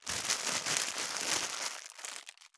622コンビニ袋,ゴミ袋,スーパーの袋,袋,買い出しの音,ゴミ出しの音,袋を運ぶ音,
効果音